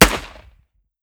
45 ACP 1911 Pistol - Gunshot B 001.wav